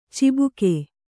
♪ cibuke